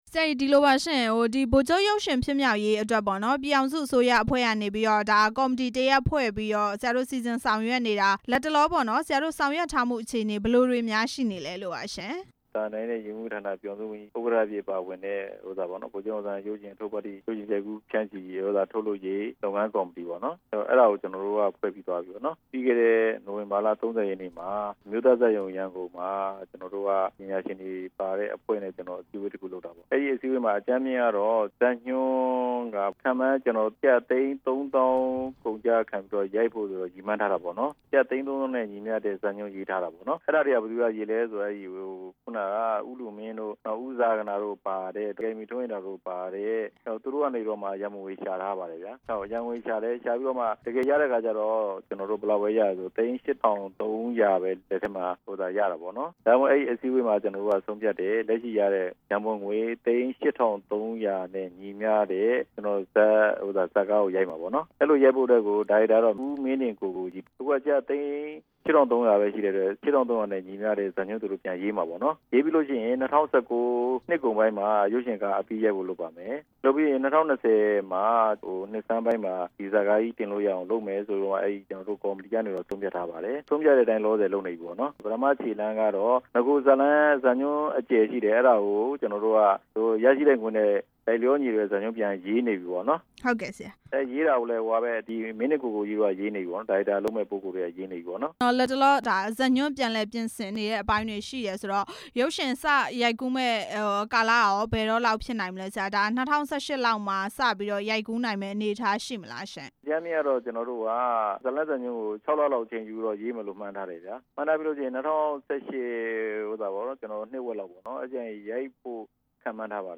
ဗိုလ်ချုပ်အောင်ဆန်းရုပ်ရှင် ဖြစ်မြောက်ရေးအကြောင်း မေးမြန်းချက်